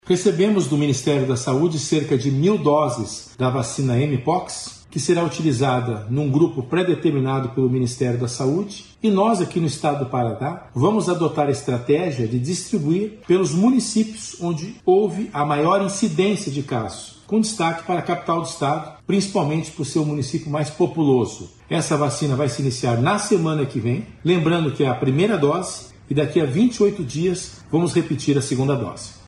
Sonora do secretário da Saúde, César Neves, sobre a vacinação contra a Mpox